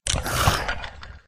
PixelPerfectionCE/assets/minecraft/sounds/mob/stray/idle2.ogg at mc116